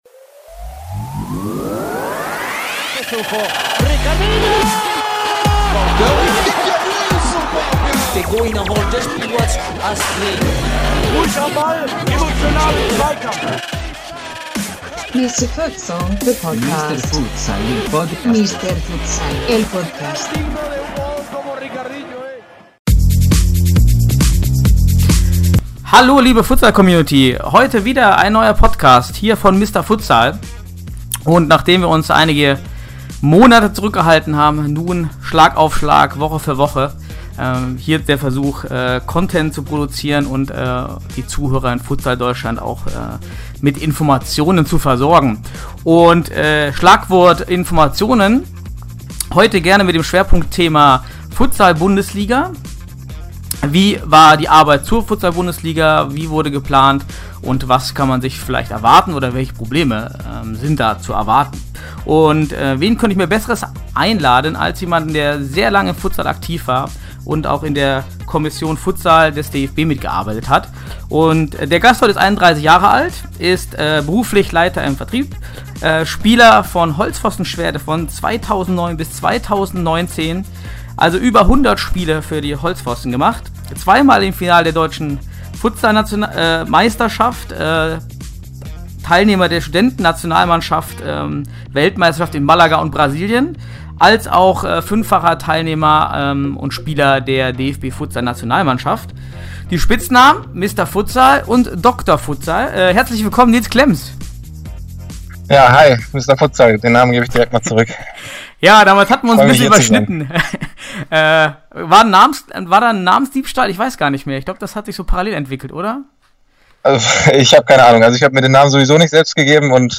Mister Futsal ist der Deep-Dive in den deutschen Futsal. Hier diskutieren drei Experten aktuelle Themen, Taktik und Strukturen